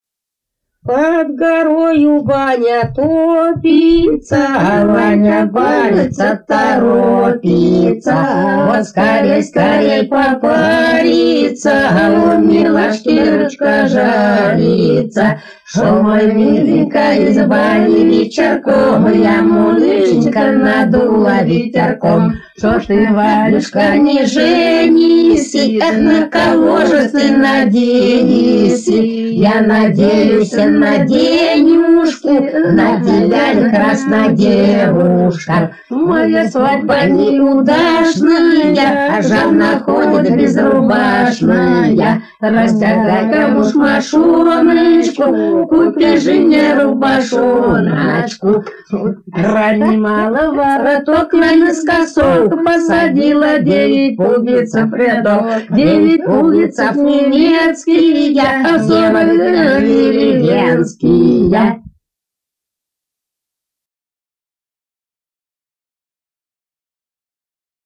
Народные песни Касимовского района Рязанской области «Под горою баня», плясовая.